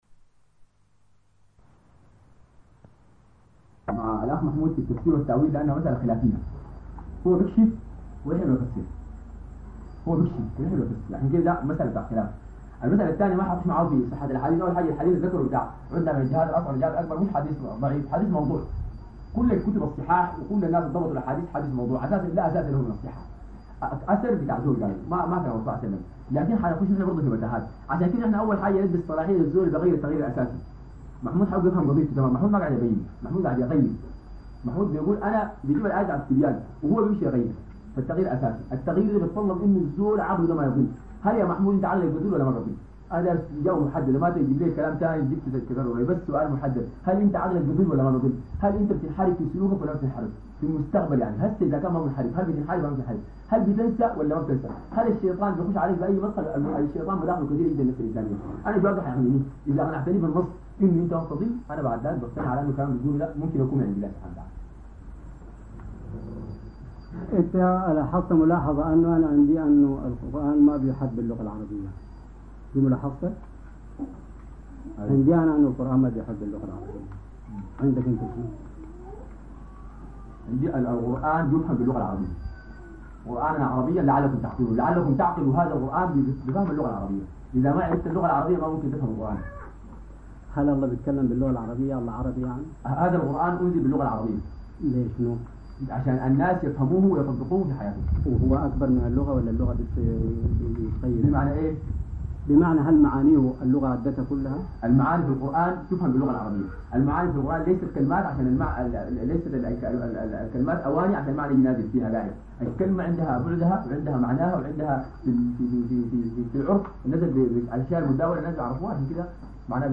امدرمان - مدينة المهدية